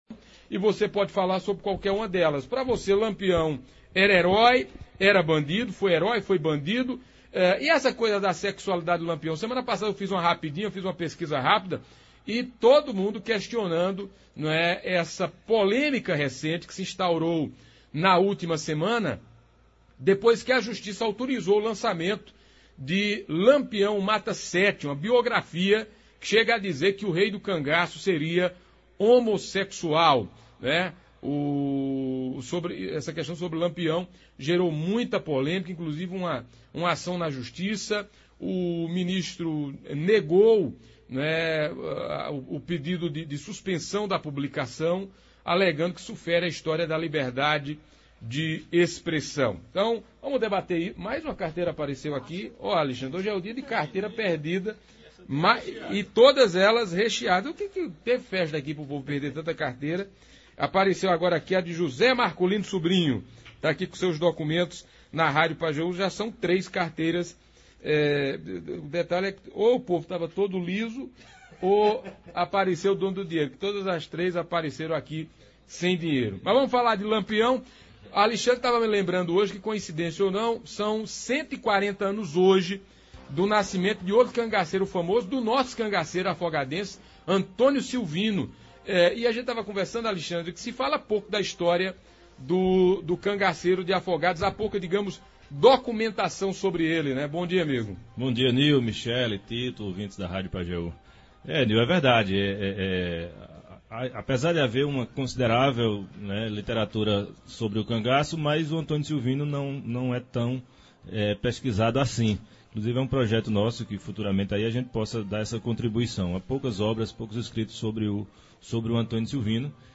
Foi hoje no Debate das Dez, da Rádio Pajeú.